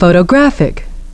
photo’graphic <)) and